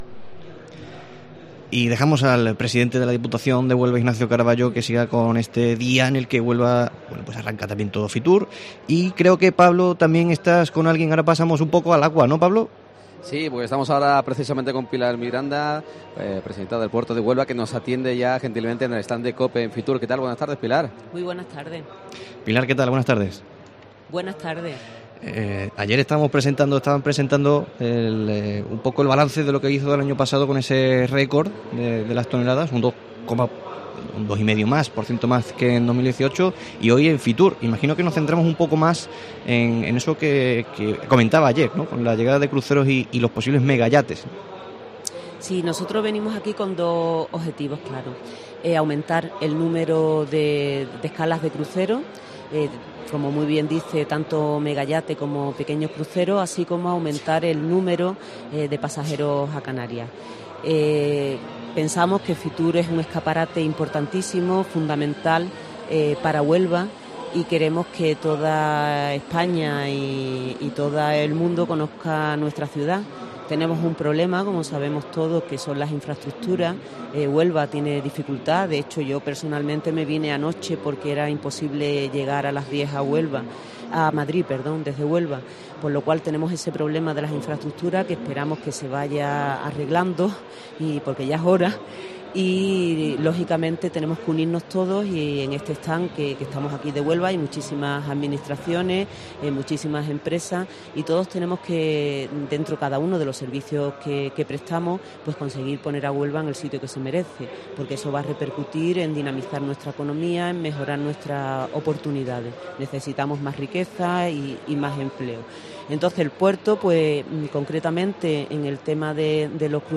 Pilar Miranda, presidenta de la Autoridad Portuaria, atiende a COPE Huelva desde FITUR donde buscará promocionar el puerto como destino de cruceros.